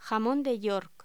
Locución: Jamón de York
voz